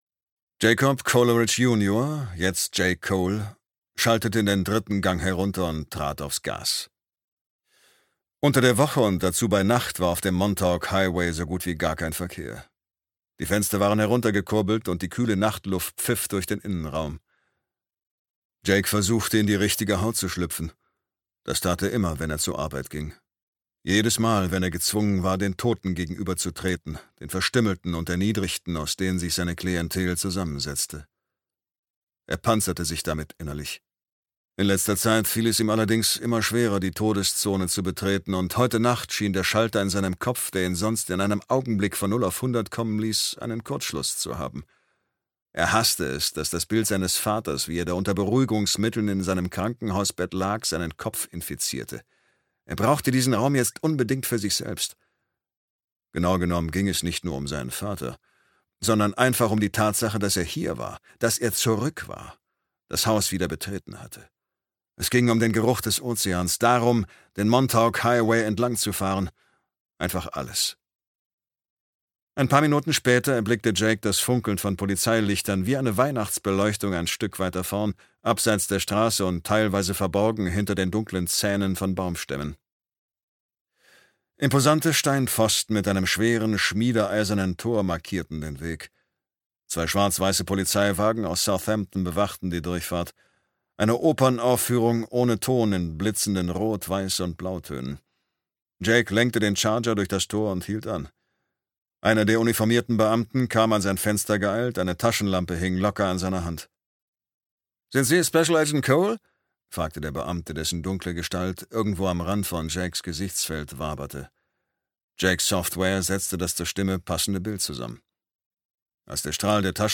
Bloodman - Robert Pobi - Hörbuch